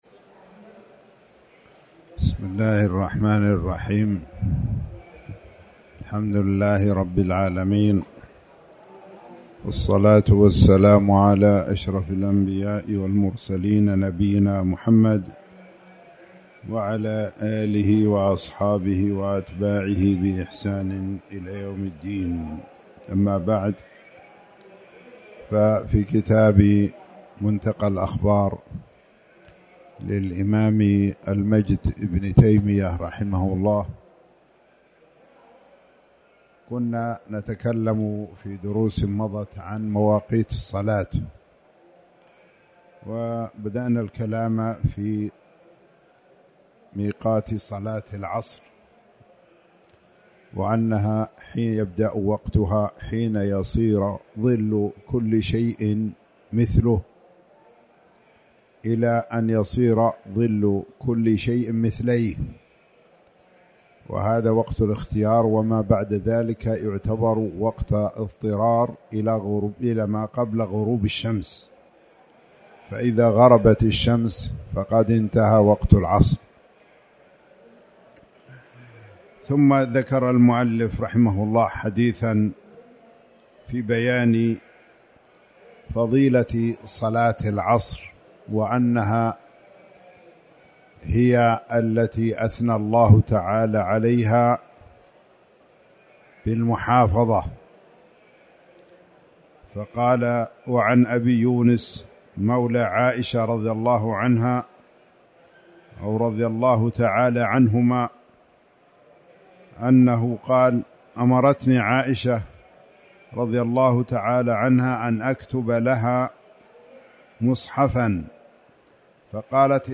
تاريخ النشر ١٢ شوال ١٤٣٩ هـ المكان: المسجد الحرام الشيخ